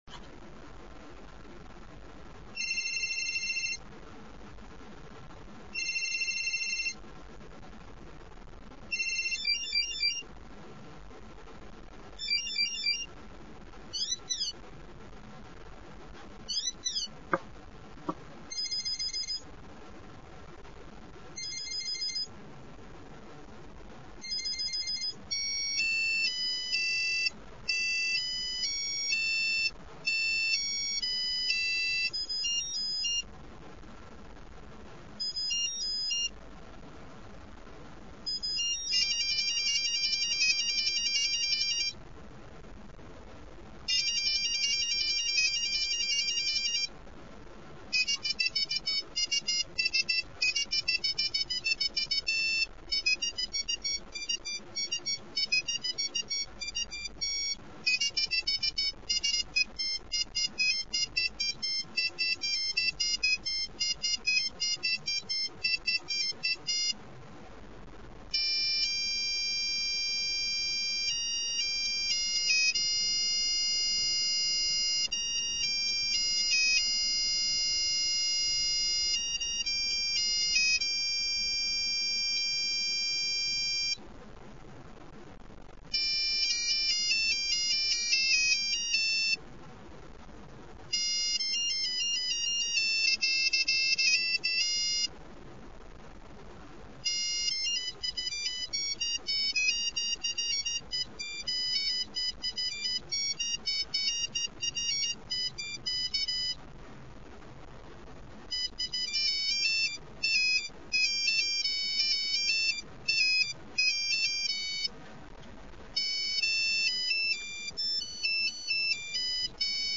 Всего в телефоне присутствует 27 звуковых сигналов (